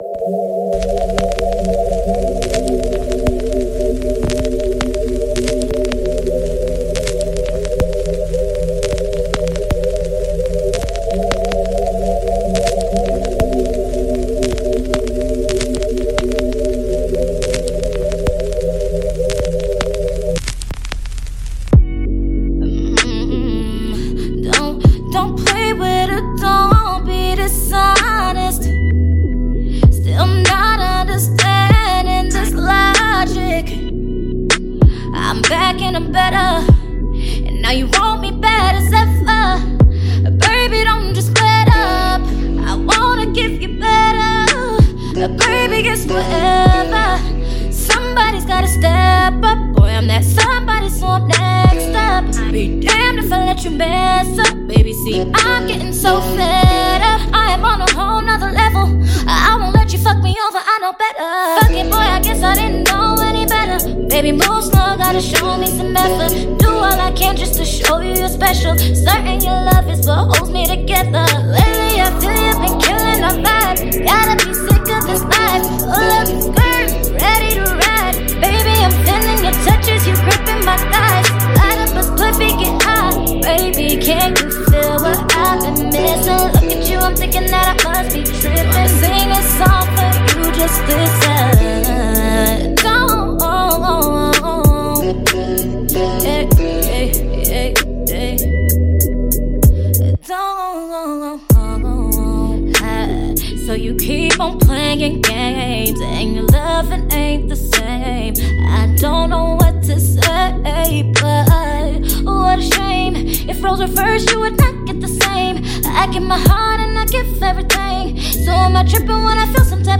RnB
cover